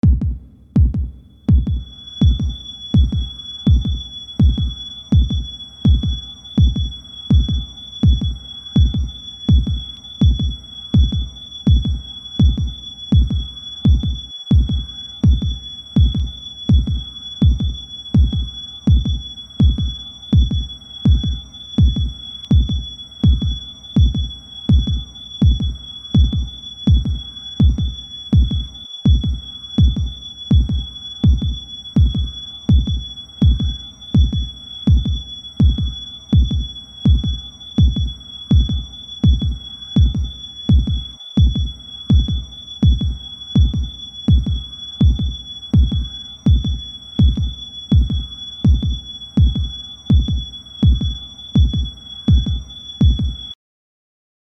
【効果音】瀕死状態 - ポケットサウンド - フリー効果音素材・BGMダウンロード